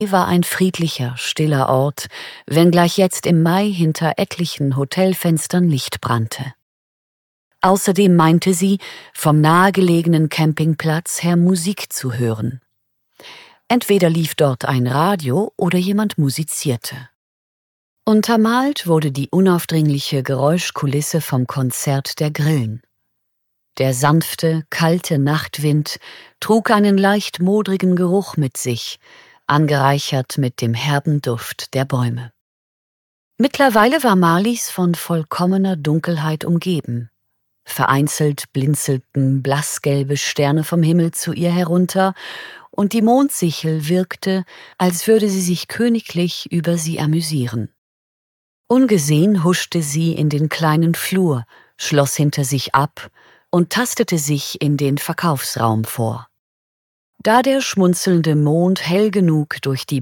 Frühlingsfunkeln am Liliensee - Hörbuch